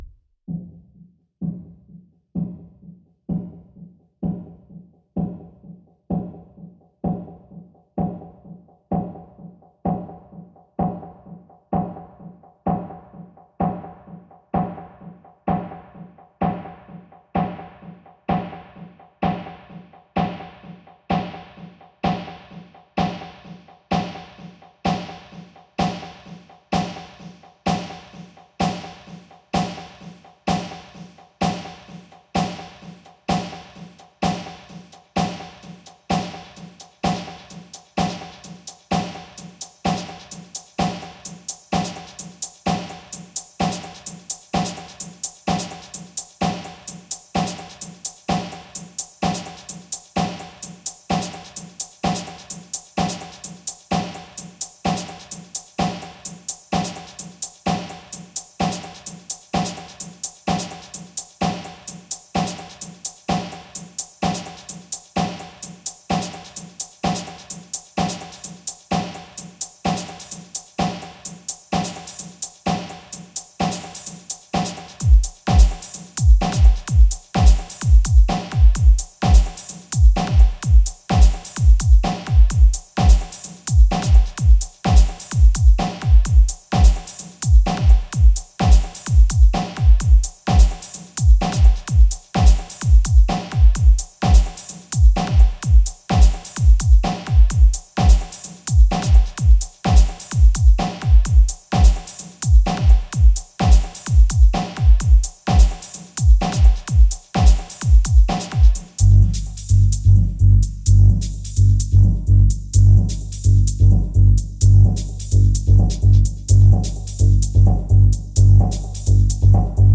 Virgen del Rosario Church in Itagua Paraguay